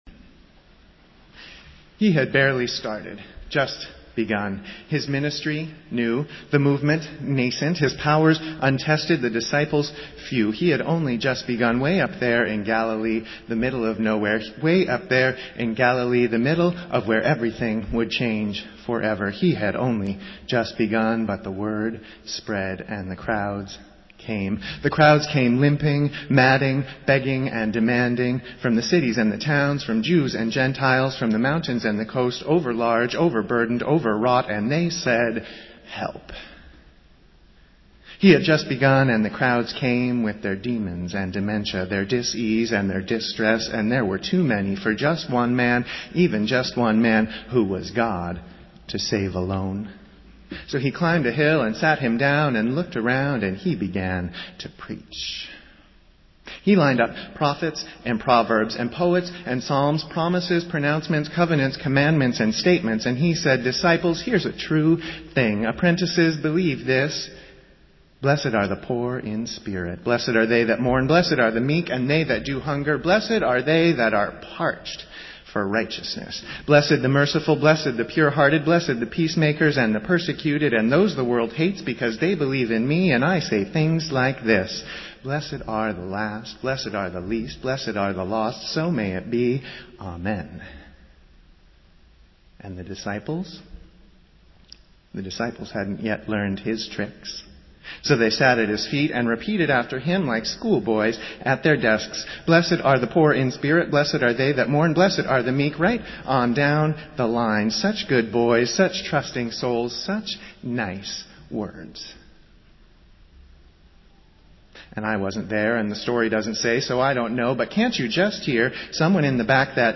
Festival Worship - Fourth Sunday after Epiphany